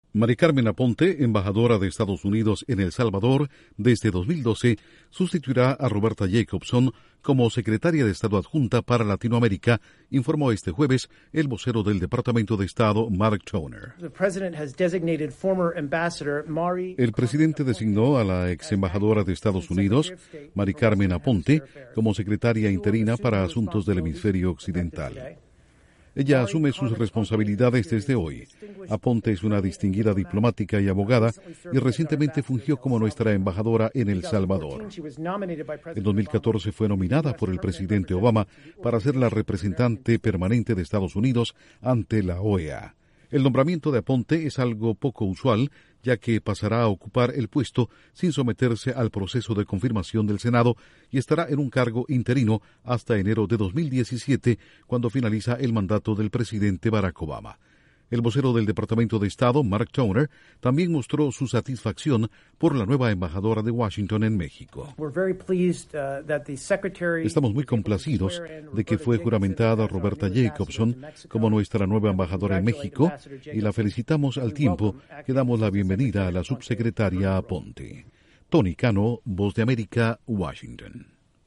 El presidente Barack Obama nombra nueva secretaria de Estado adjunta para América Latina. Informa desde la Voz de América